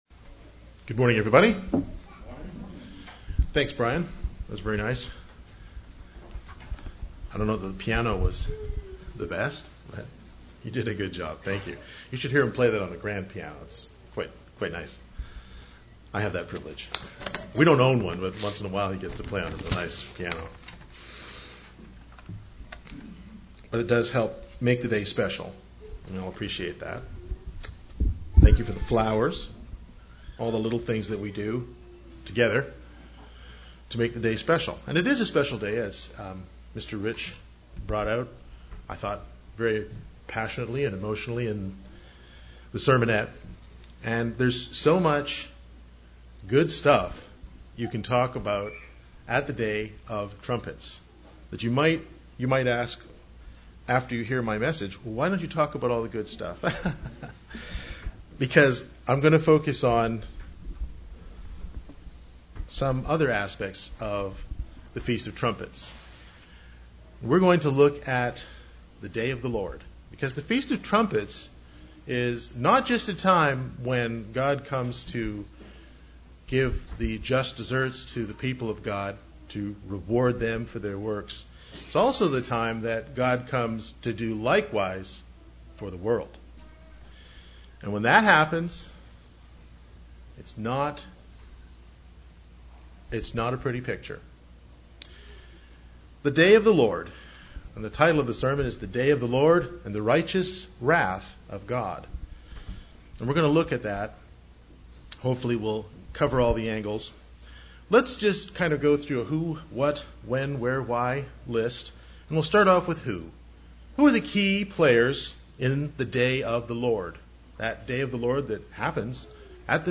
UCG Sermon Notes The Day of the Lord & The Righteous Wrath of God Who are the key players in the Day of the Lord Jesus Christ who returns to earth to execute the judgment of God.